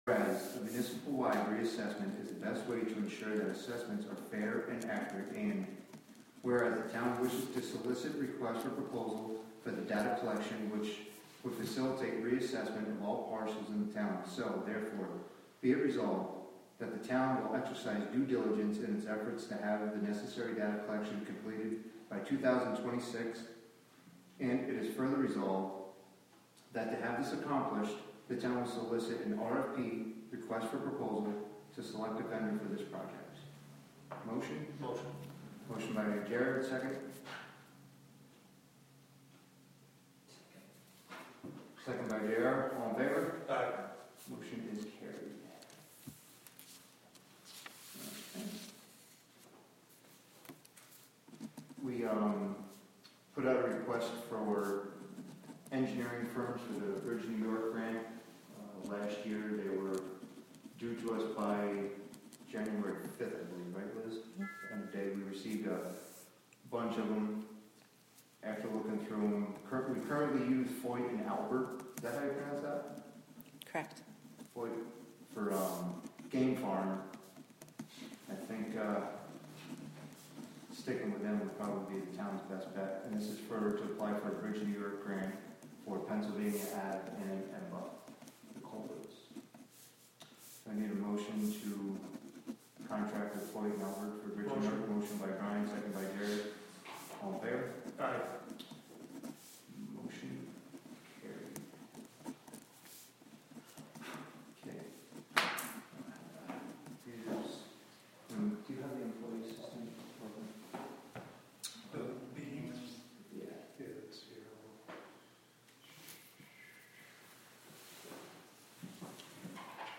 Live from the Town of Catskill: January 17, 2024 Town Board Committee Meeting (Audio)